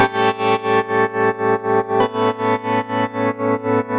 Index of /musicradar/sidechained-samples/120bpm
GnS_Pad-MiscB1:8_120-A.wav